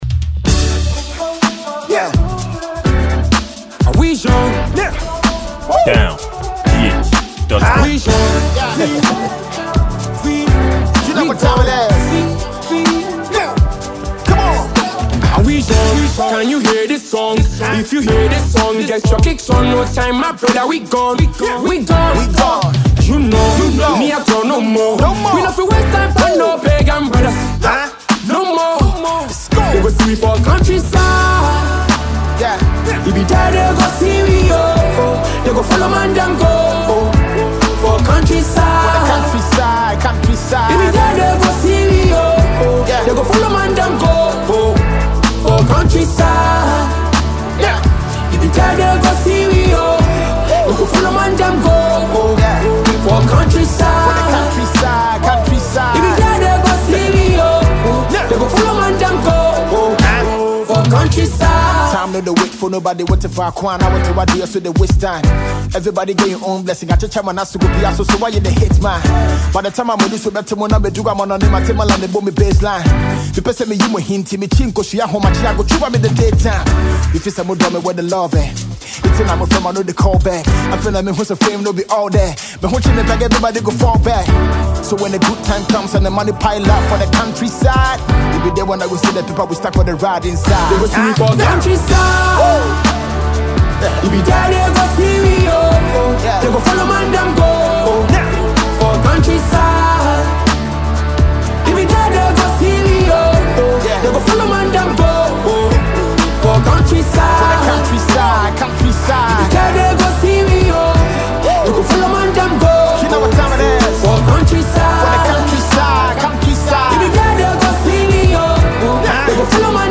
Highly-rated Ghanaian rapper